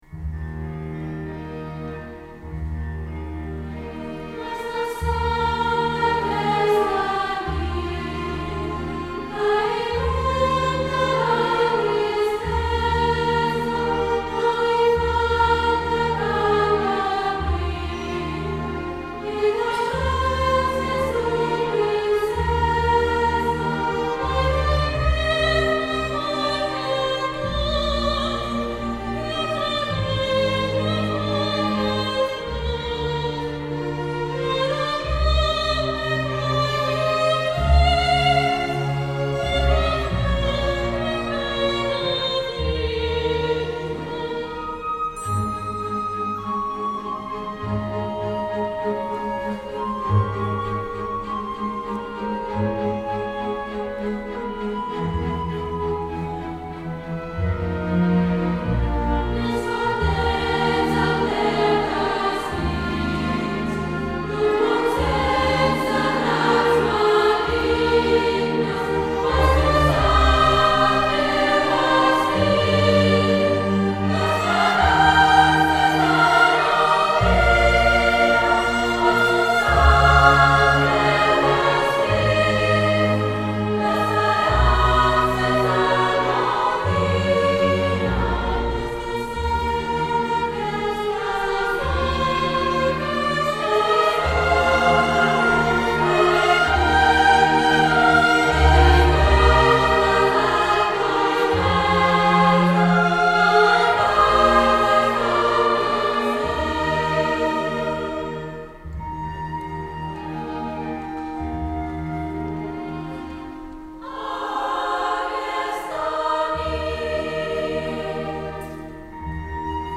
Cantata per a orquestra i cor jove
Aquí podeu sentir la Canço dels nens, enregistrada l’Abril del 2006 en l’acte final de la setmana medieval de Montblanc.